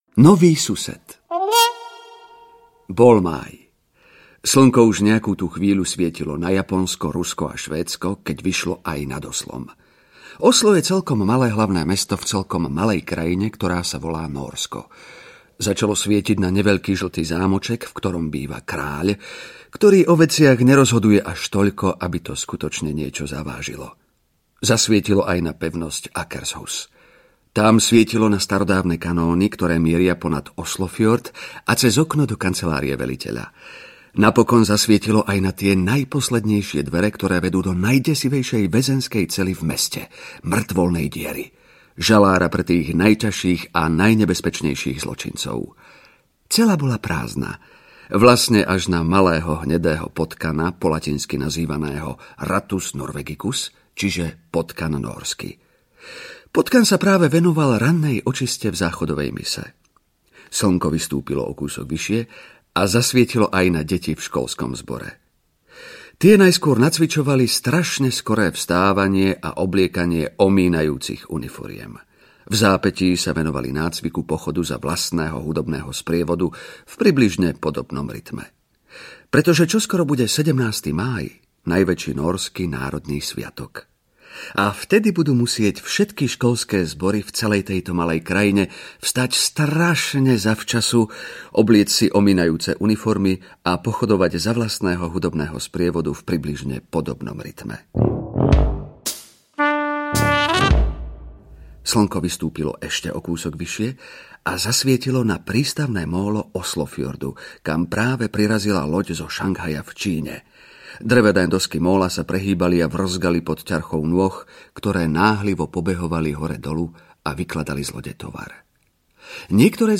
Prdiprášok doktora Proktora audiokniha
Ukázka z knihy